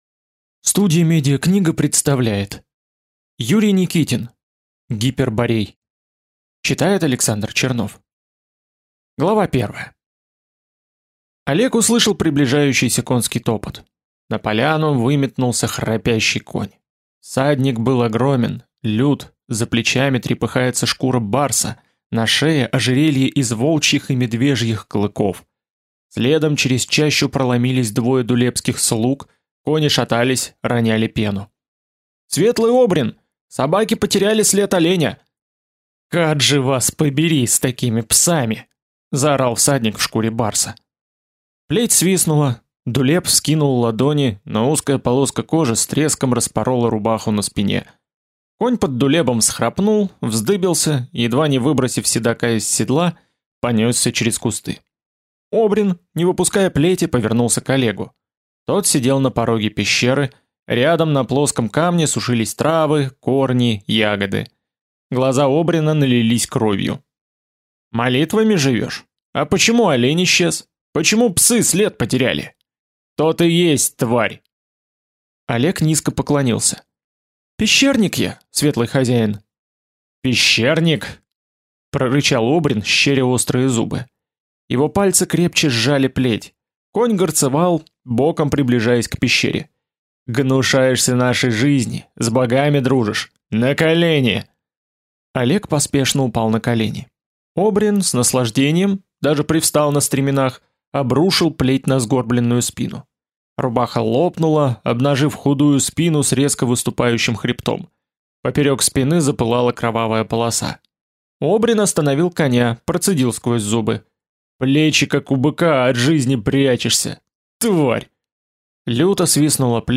Аудиокнига Гиперборей | Библиотека аудиокниг